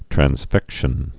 (trăns-fĕkshən)